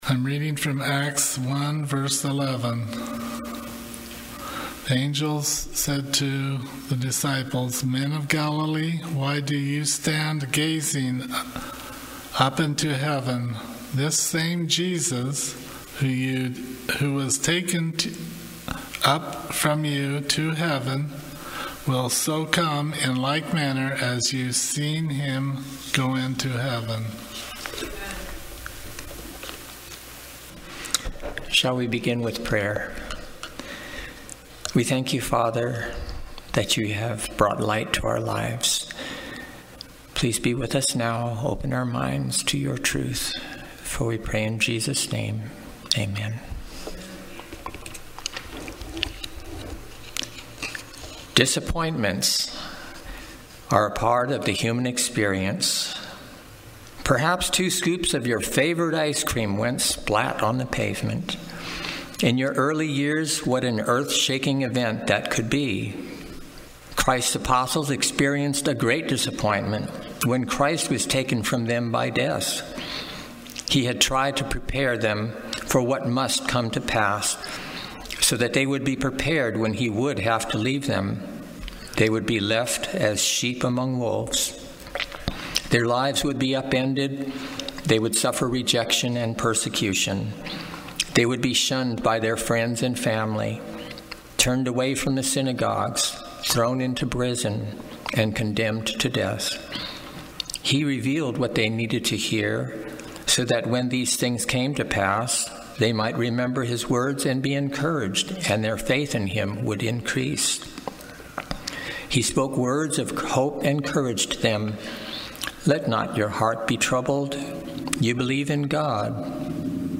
Seventh-day Adventist Church, Sutherlin Oregon
Sermons and Talks 2024